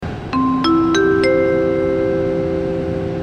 llamada-aeropuerto-supermercado.mp3